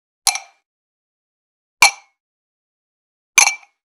90.ワイングラス【無料効果音】
ASMRコップワイン効果音
ASMR